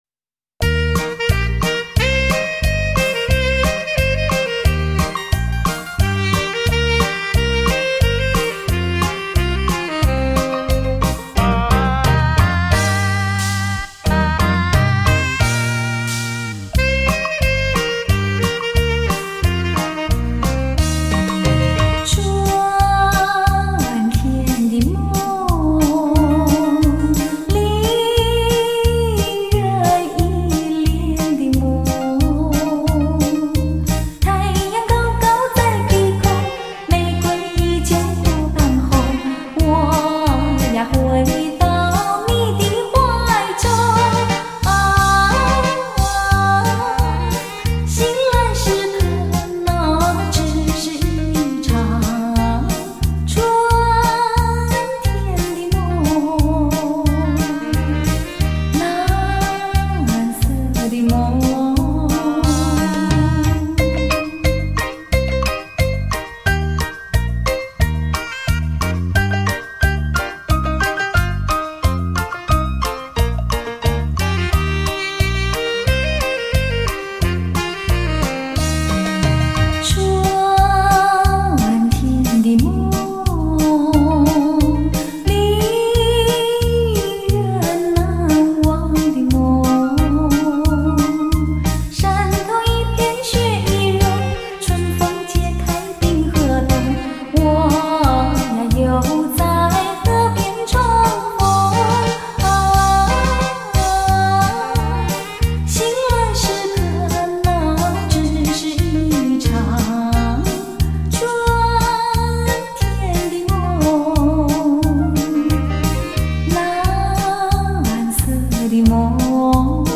吉鲁巴